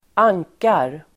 Uttal: [²'ang:kar]